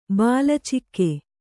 ♪ bāla cikke